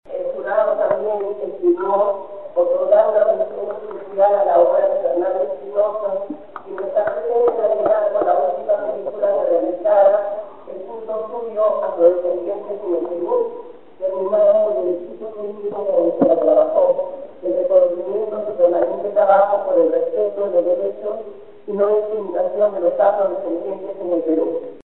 Escuchar el audio de la premiación Cimarrones.